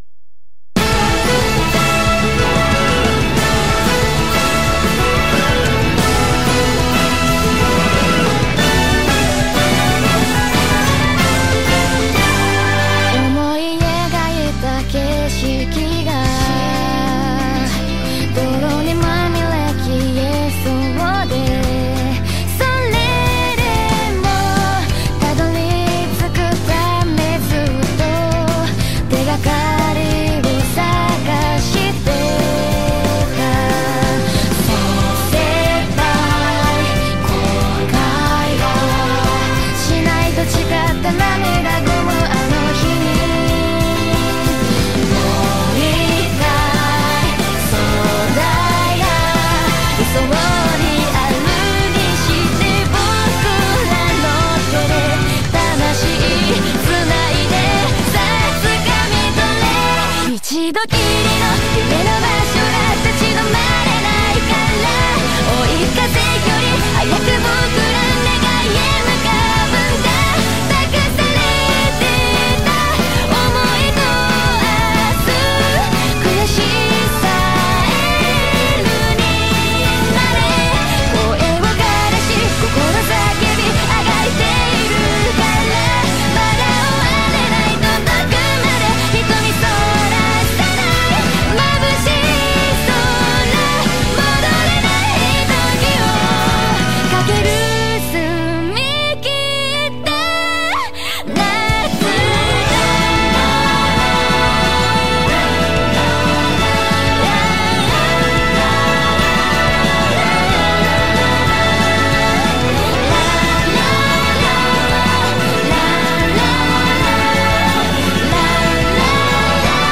BPM160-184
Audio QualityPerfect (Low Quality)